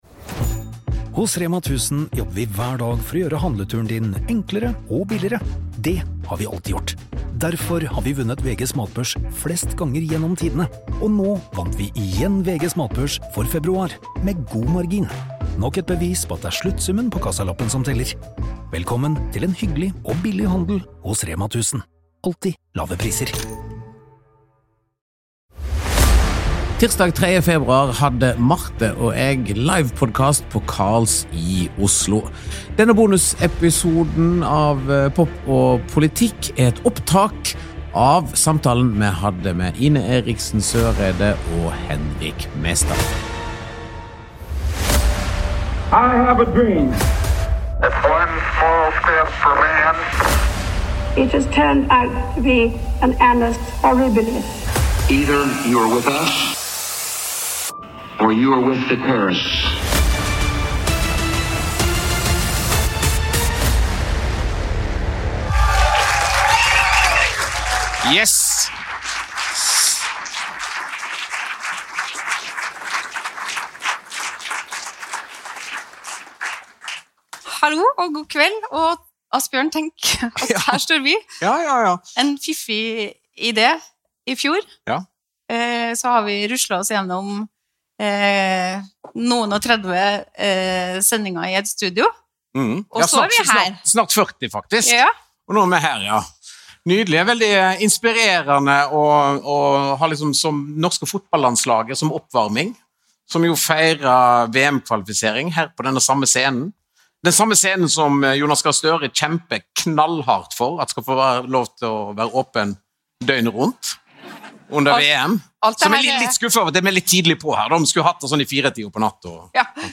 40. Live fra Carls, 3. februar